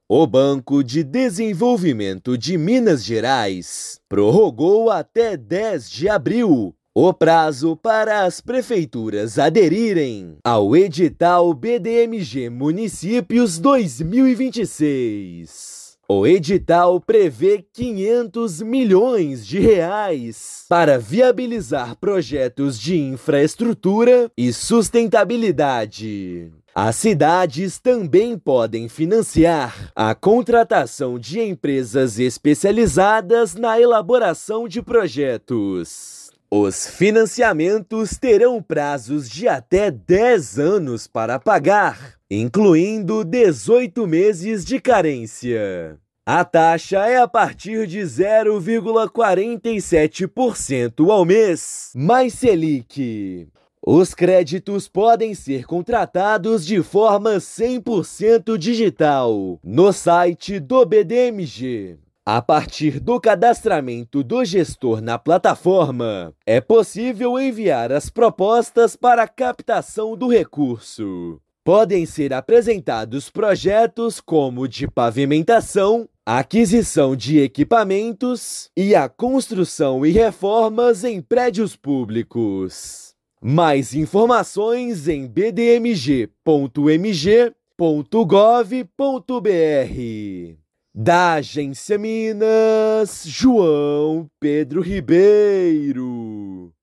São R$ 500 milhões em crédito com condições especiais para que cidades invistam em obras e elaboração de projetos. Ouça matéria de rádio.